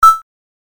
click 011
/ F｜システム電子音 / F-01 ｜システム1 シンプルな電子音 ボタン、クリックなどに
ジャン 金属質